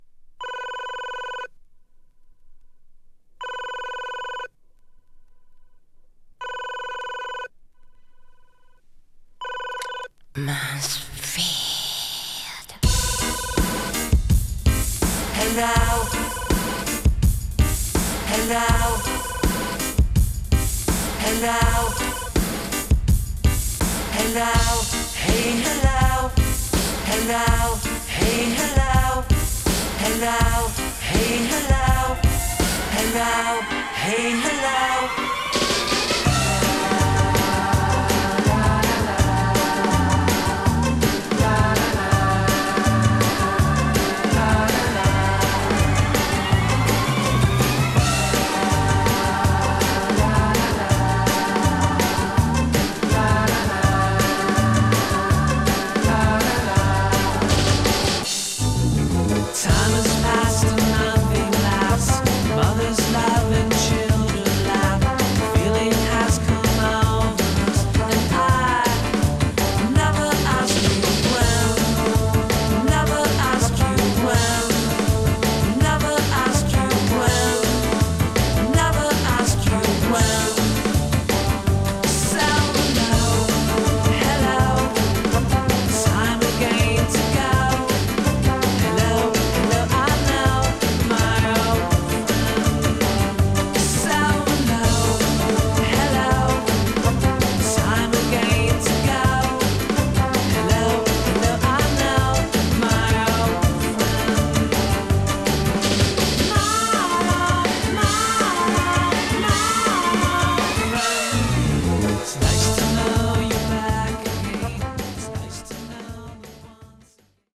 カナダ人ヴォーカリスト